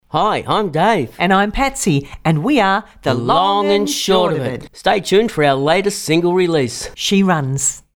dd709-the-long-and-short-of-it-radio-id-she-runs-8secs